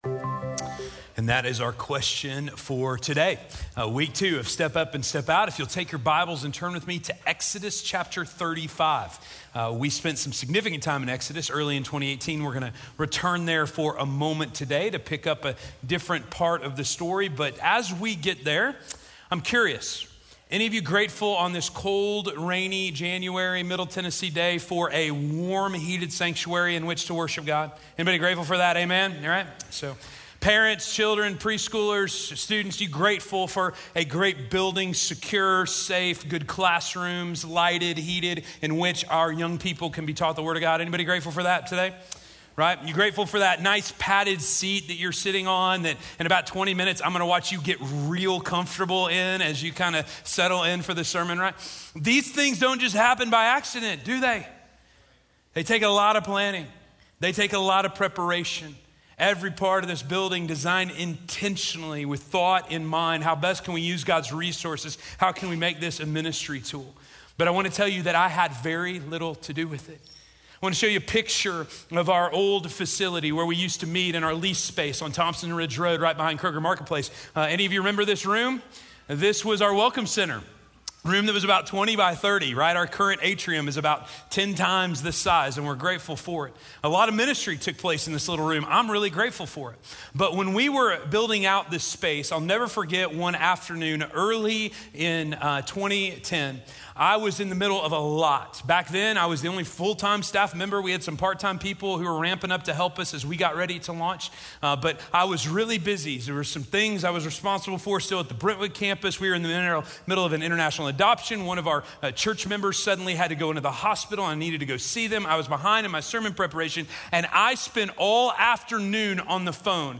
Called Out By Name - Sermon - Station Hill